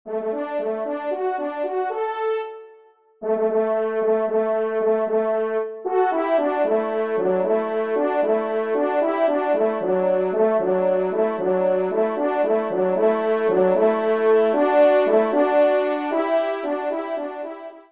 2ème Trompe